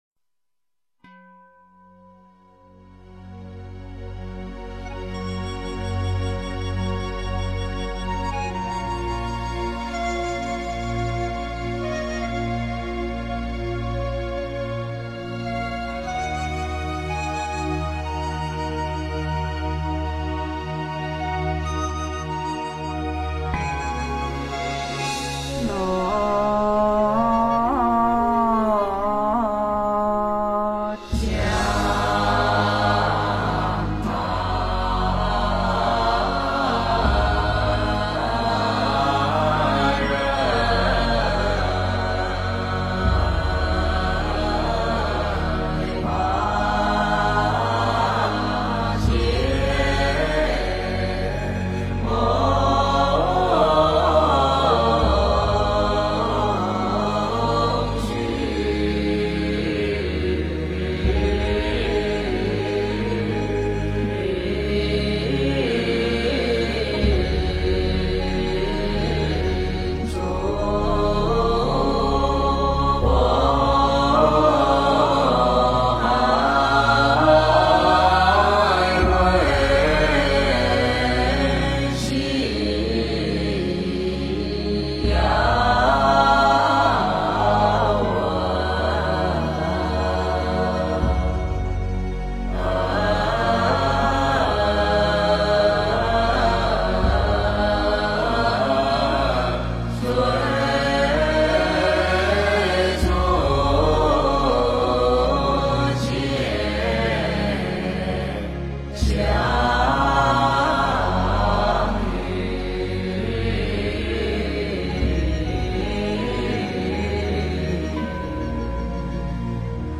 炉香赞 诵经 炉香赞--如是我闻 点我： 标签: 佛音 诵经 佛教音乐 返回列表 上一篇： 华严字母三合 下一篇： 南无大悲观世音菩萨三称 相关文章 《妙法莲华经》分别功德品第十七--佚名 《妙法莲华经》分别功德品第十七--佚名...